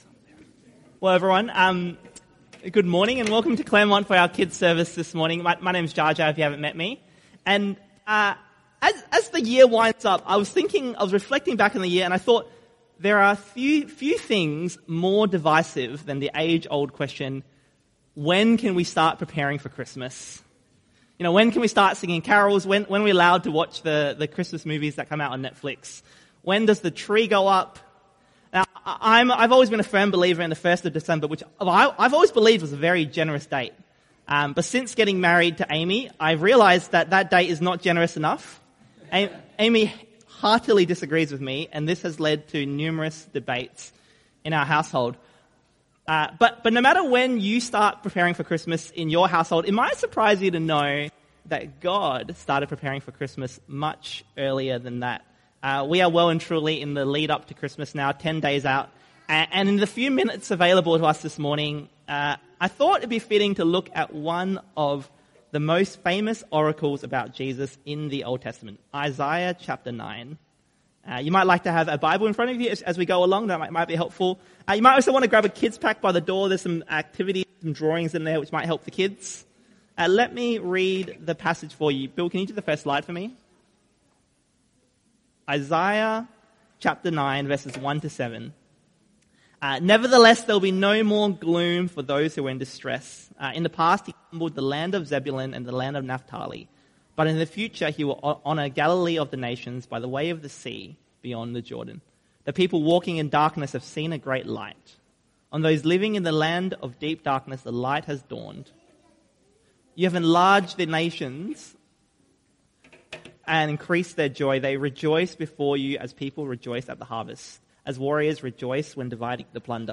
CBC Kids Christmas Service – Isaiah 9:1-7
Type: Sermons